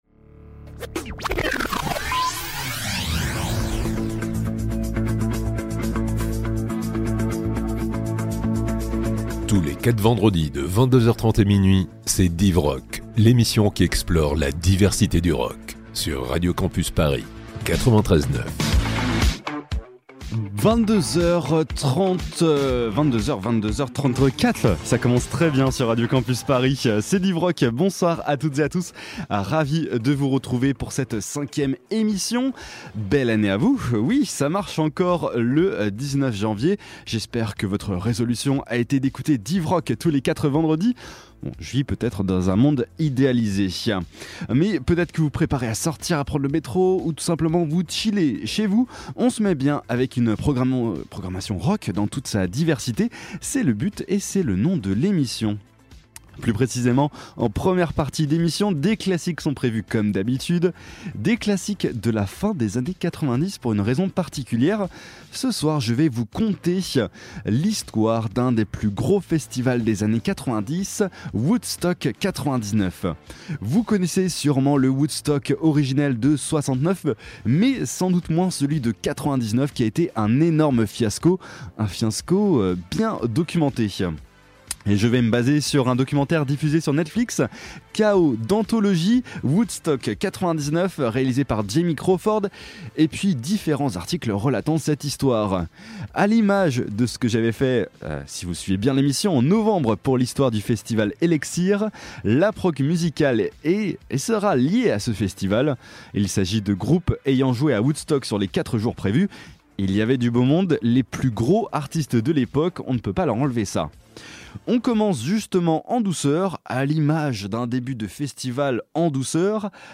Type Musicale Pop & Rock